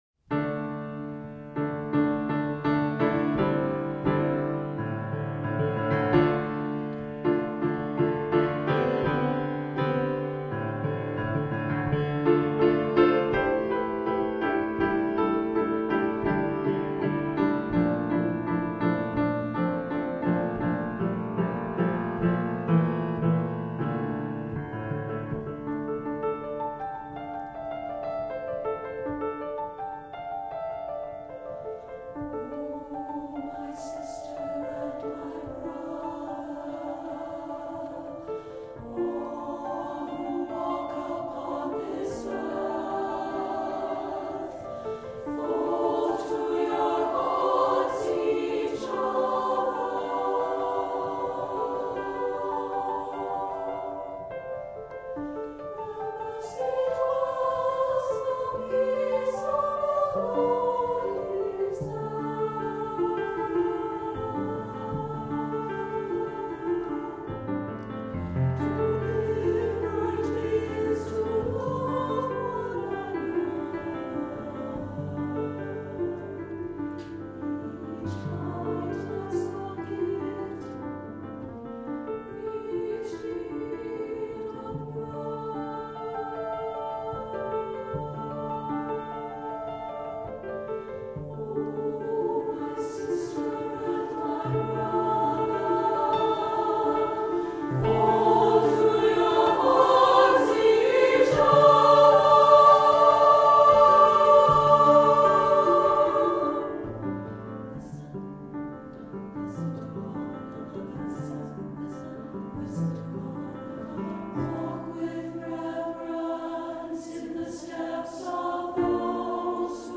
an MP3 file of a performance of this work (SSA version) by the Indianapolis Women's Chorus.